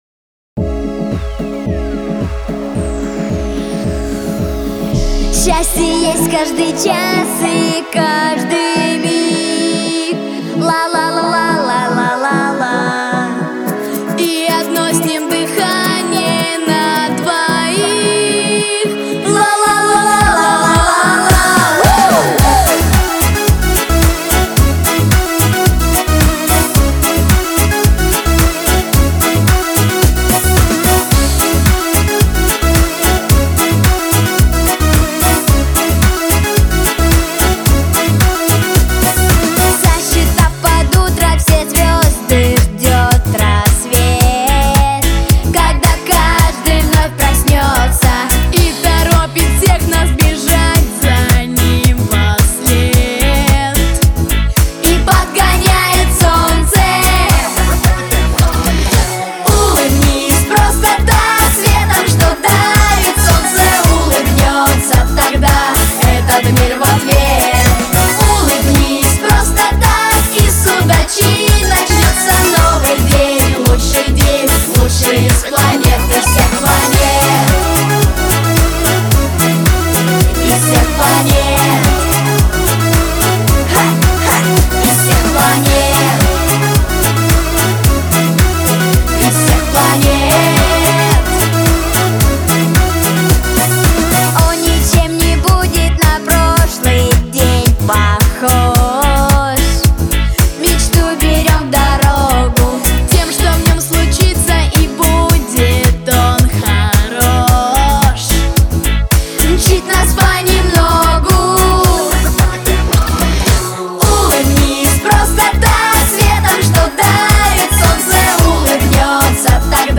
• Жанр: Поп музыка